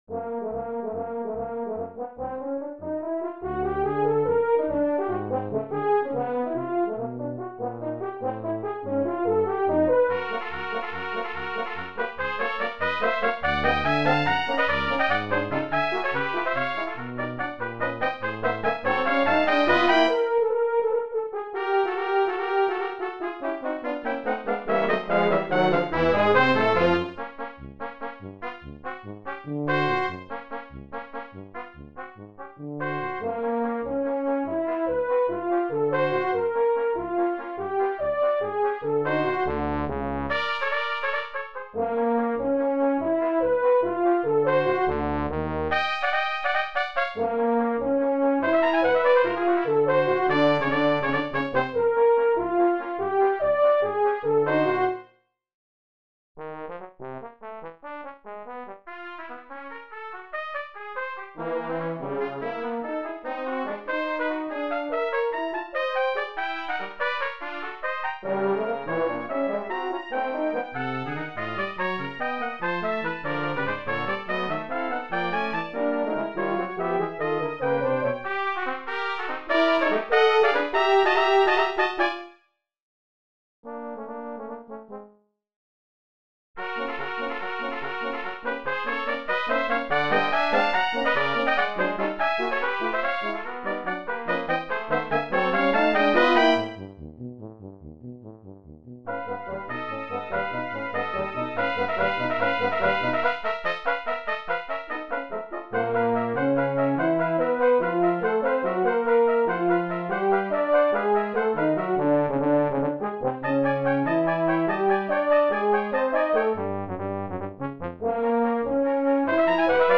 Sheet Music for Brass quintet
Original Composition
Part 1: Eb Trumpet, Bb Cornet
Part 3: F Horn
Part 4: Trombone – Bass clef
Part 5: Tuba